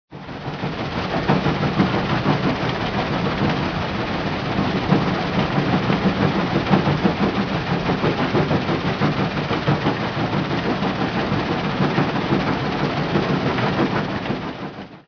ore-processing-machine.ogg